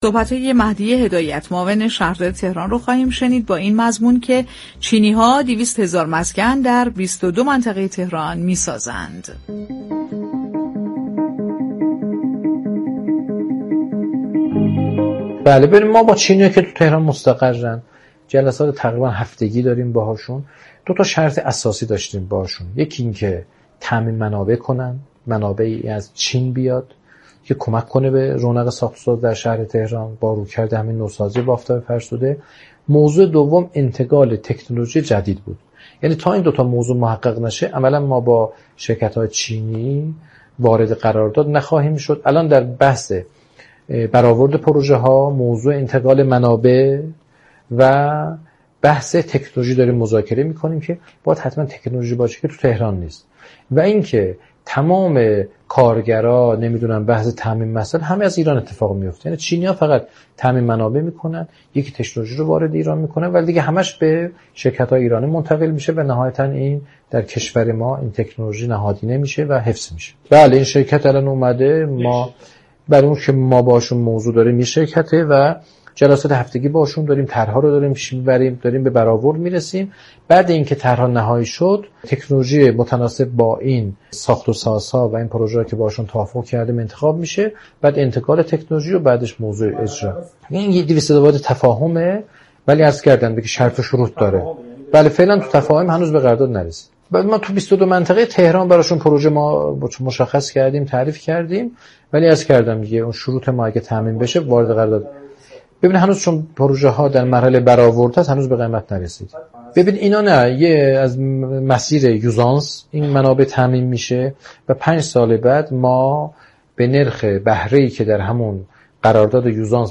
به گزارش پایگاه اطلاع رسانی رادیو تهران، مهدی هدایت معاون شهردار تهران در گفت و گو با «بازار تهران» درخصوص ساخت 200 هزار مسكن در مناطق 22 گانه تهران توسط چینی‌ها اظهار داشت: چینی‌ها در تهران مستقر هستند و به صورت هفتگی جلساتی با آنها برگزار می‌كنیم.